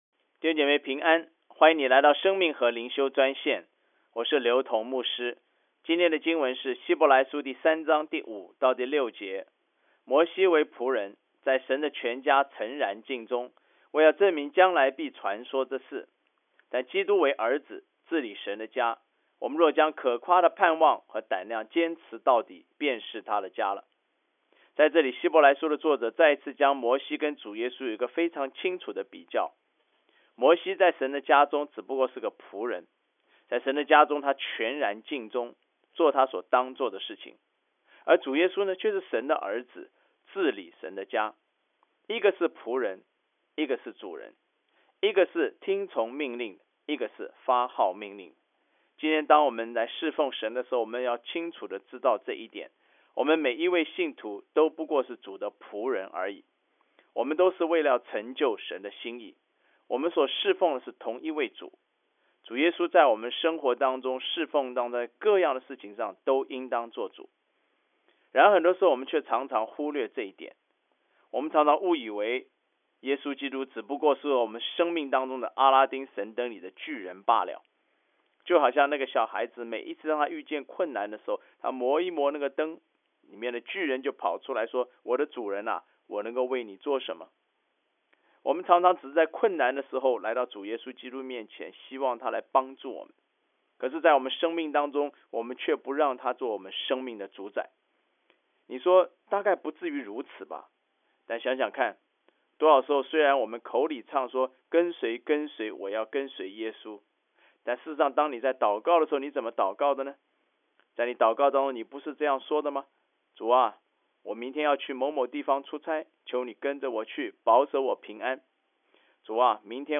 藉着每天五分钟电话分享，以生活化的口吻带领信徒逐章逐节读经。